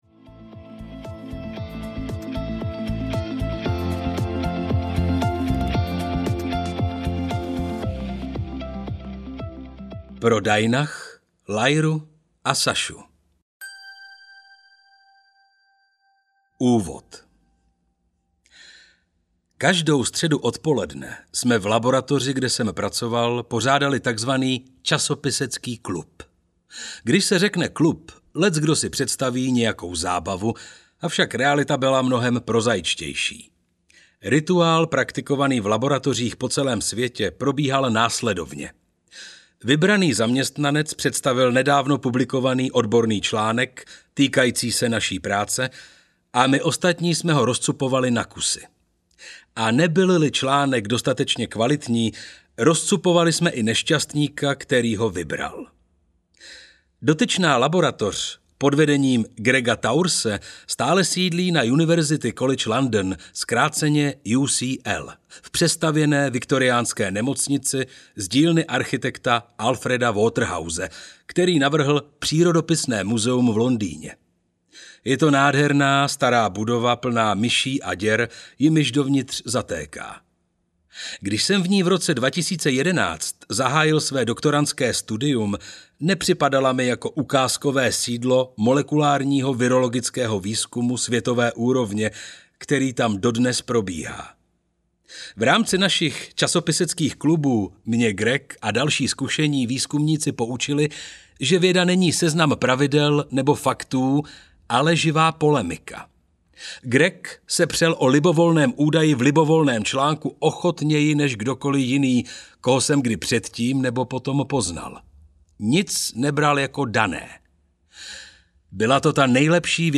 Ultrazpracovaní lidé audiokniha
Ukázka z knihy